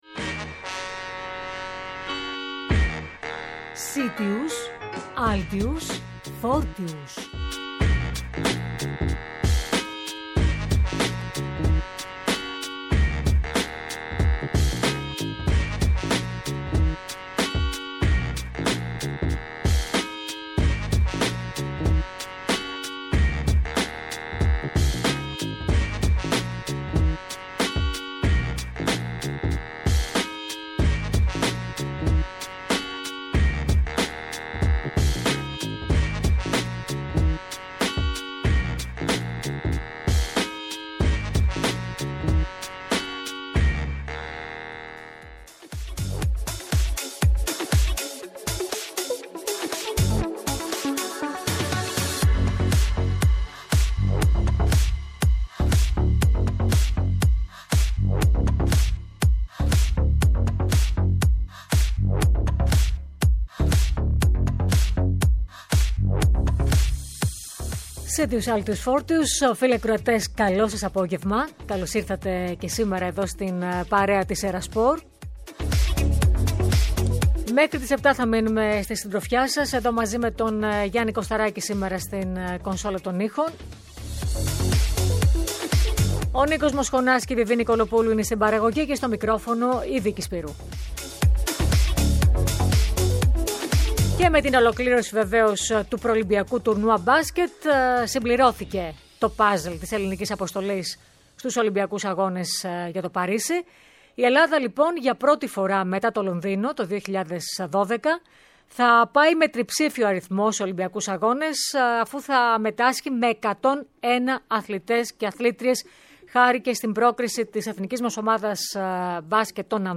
Κοντά μας σήμερα ήταν: ο παγκόσμιος πρωταθλητής του ακοντισμού Κώστας Γκατσιούδης, ο οποίος ως αρχηγός αποστολής της εθνικής κ18 που μετείχε στο Βαλκανικό στο Μάριμπορ, της Σλοβενίας, μας μίλησε για τις επιτυχίες των νεών αθλητών μας, ενώ θυμήθηκε στιγμές από τους Ολυμπιακούς αγώνες που μετείχε.